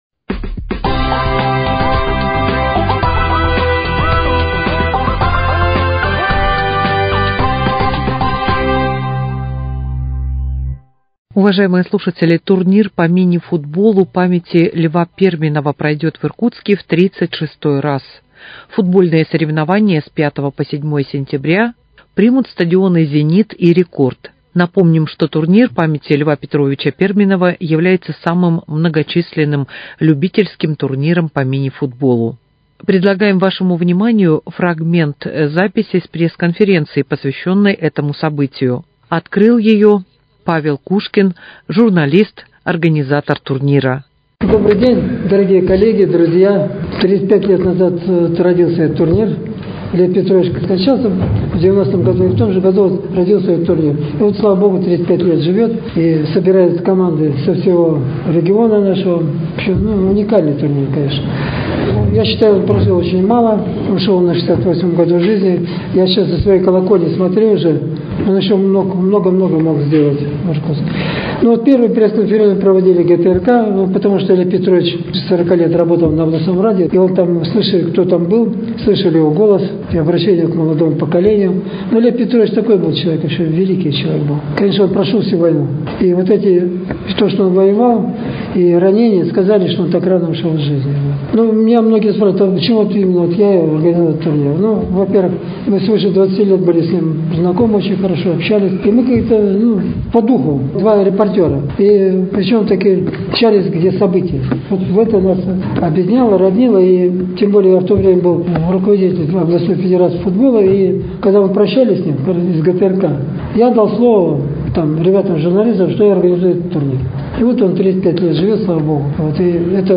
Ожидается, что участие в нём примут 50 любительских команд из разных городов Иркутской области. Вашему вниманию фрагмент записи с пресс-конференции, посвящённой этому значимому спортивному событию.